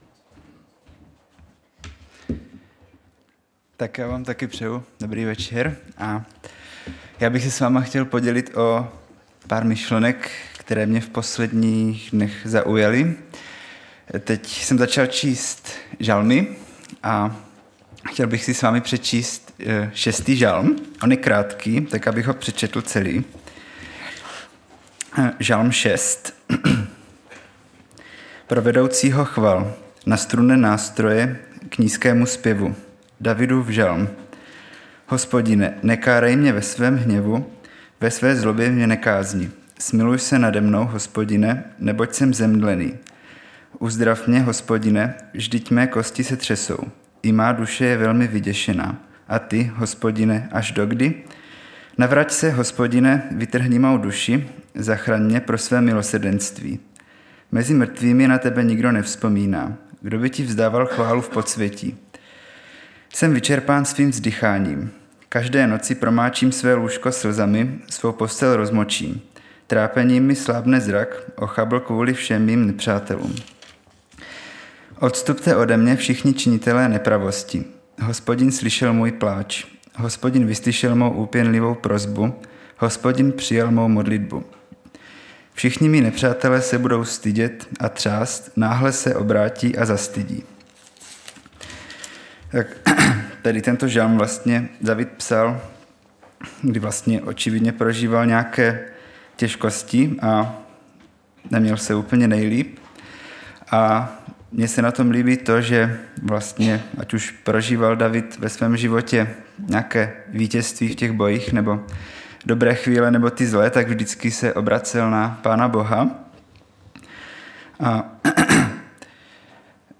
Středeční vyučování
Záznamy z bohoslužeb